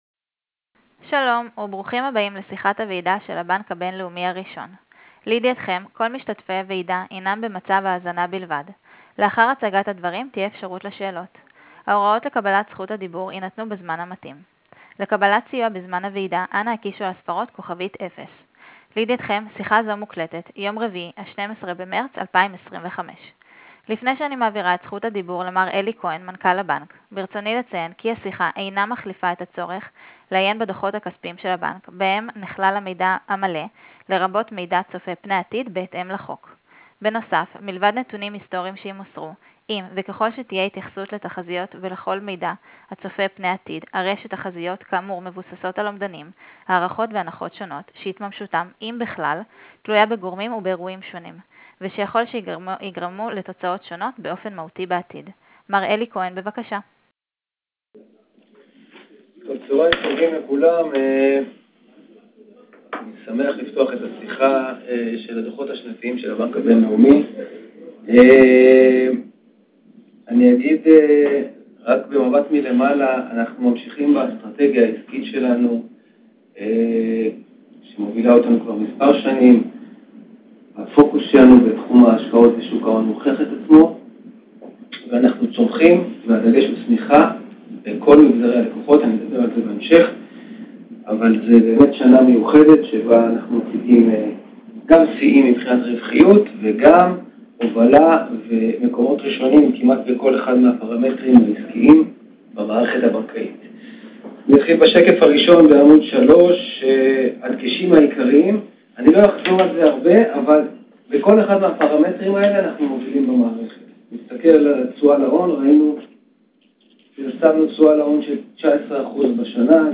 שיחת ועידה